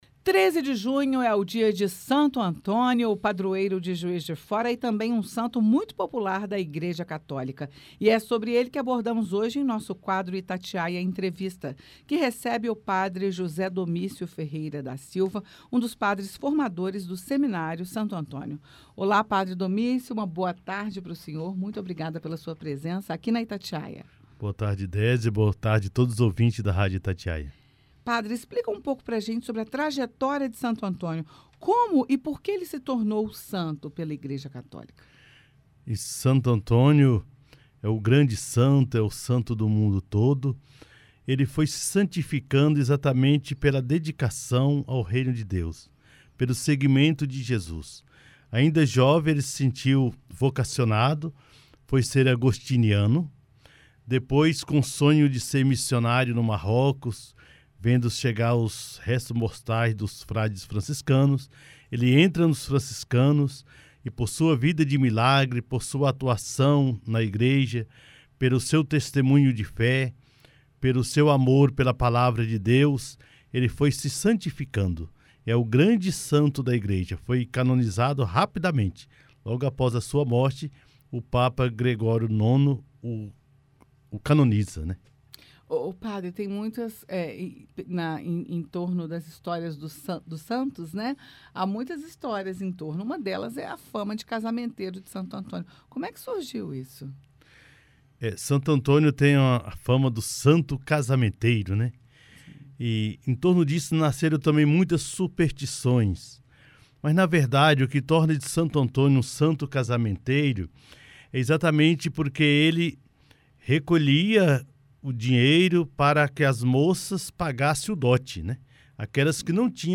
Santo Antônio: Padre detalha a história e as tradições do santo
12.06_Itatiaia-Entrevista_Dia-de-Santo-Antonio-integra.mp3